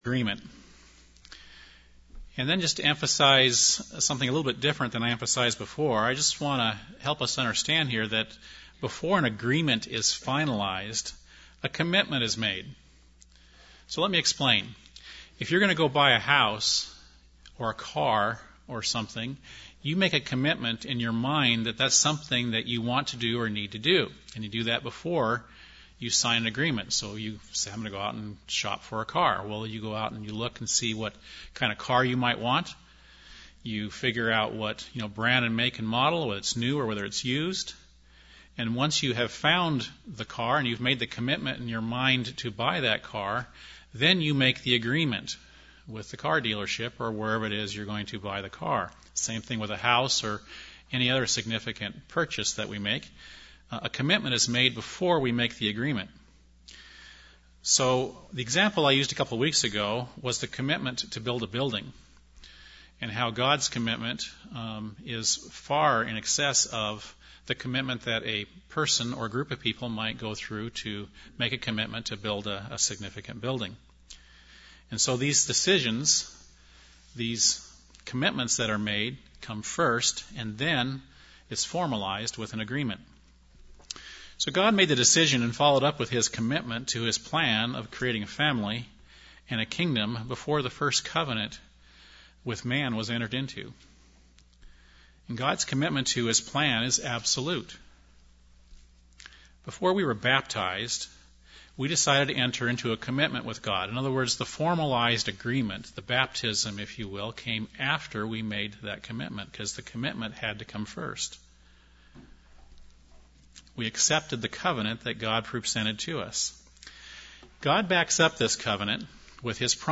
Sermons
Given in Burlington, WA